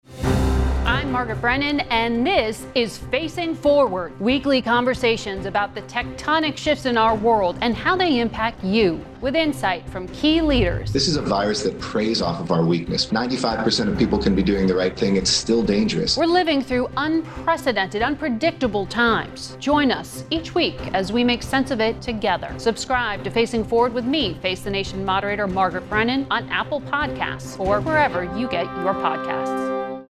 From the team that produced 2020’s most-watched Sunday morning public affairs program comes FACING FORWARD, a new podcast hosted by FACE THE NATION moderator Margaret Brennan, featuring weekly conversations about the tectonic shifts in our world and how they impact every American.